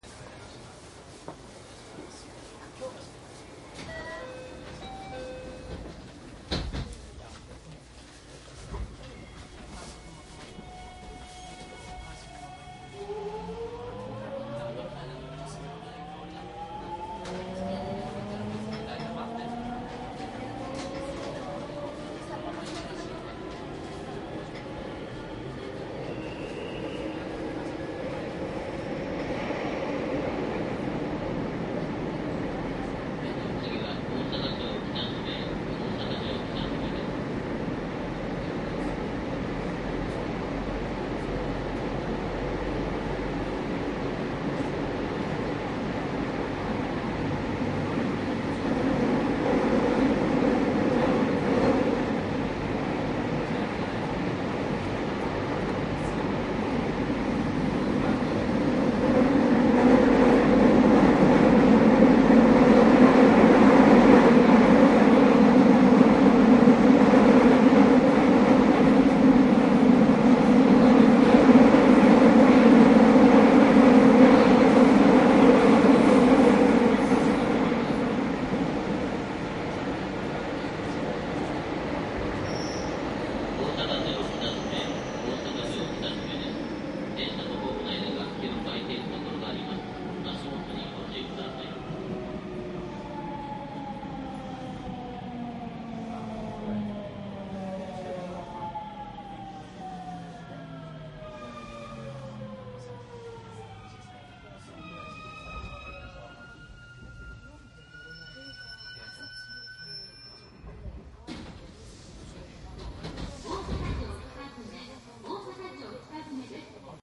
内容は♪JR宝塚線321系・ 神戸線 ・東西線 207系1000番台走行音ＣＤです。
■【各駅停車】大阪→高槻 モハ320－43
サンプル音声 モハ320－43.mp3
マスター音源はデジタル44.1kHz16ビット（マイクＥＣＭ959）で、これを編集ソフトでＣＤに焼いたものです。